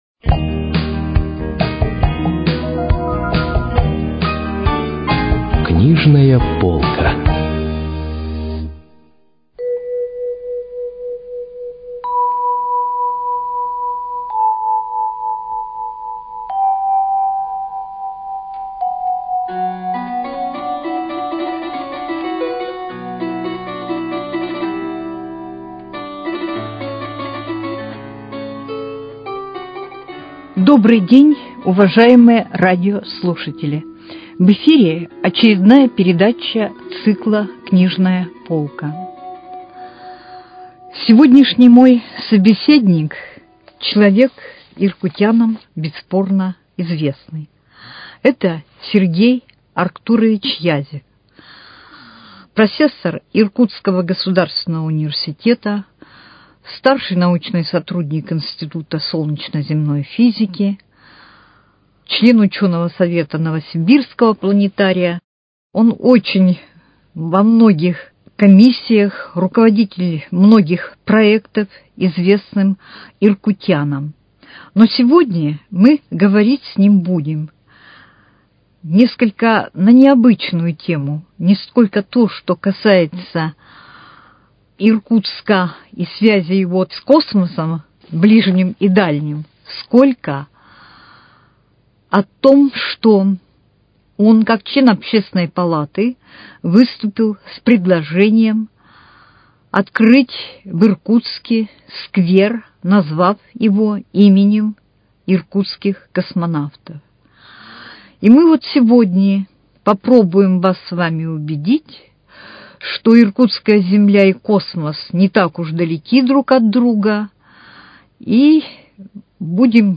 Передача из цикла «Книжная полка».